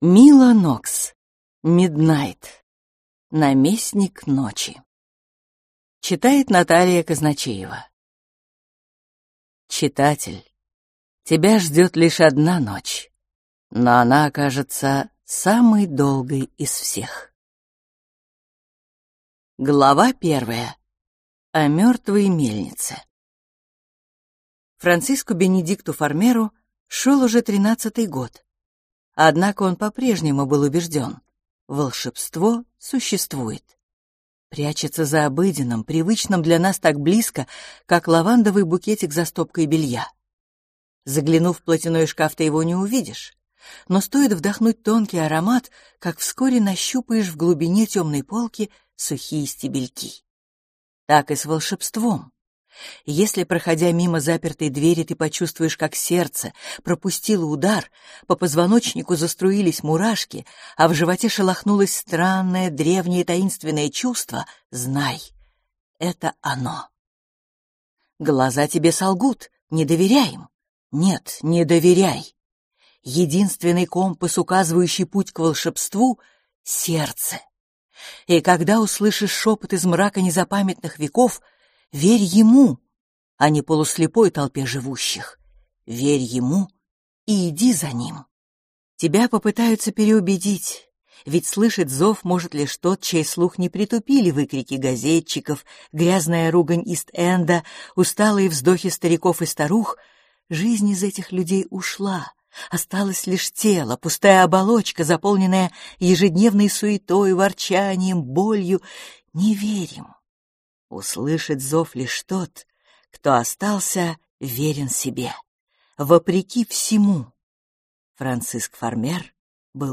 Аудиокнига Наместник ночи | Библиотека аудиокниг